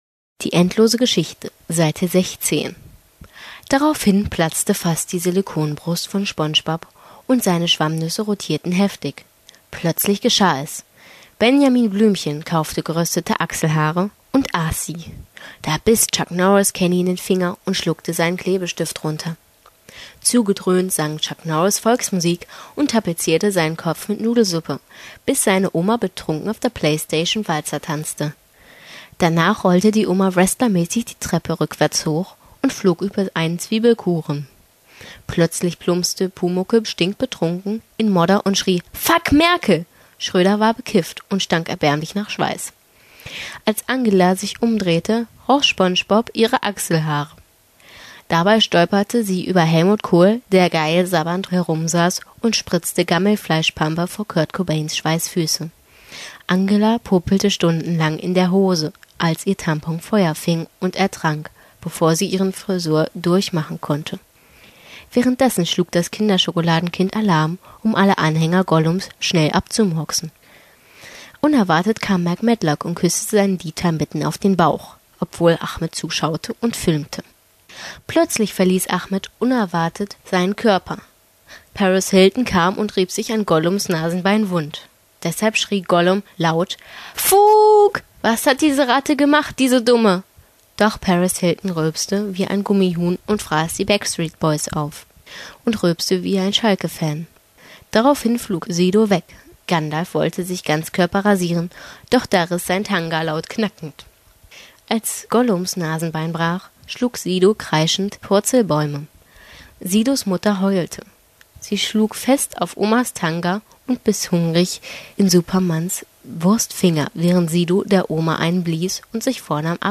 Das Hörbuch zur endlosen Story, Seite 16